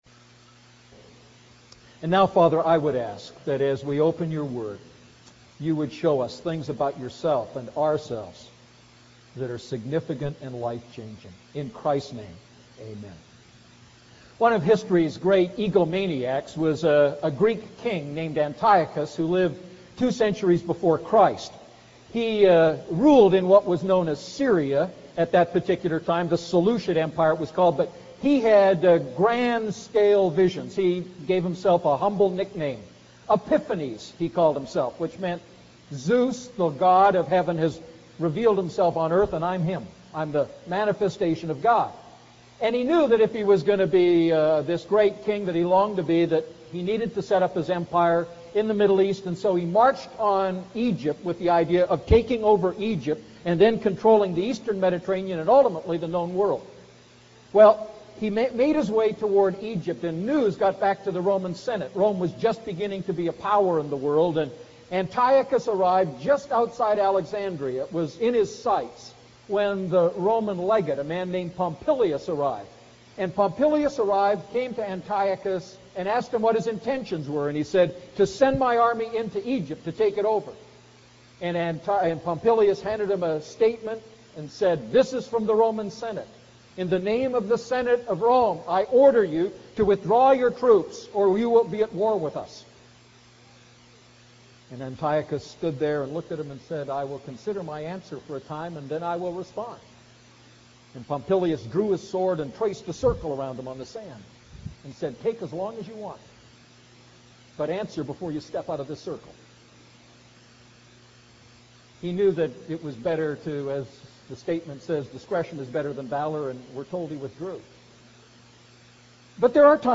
A message from the series "Luke Series I."